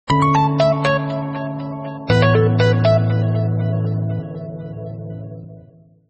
Light_hearted.ogg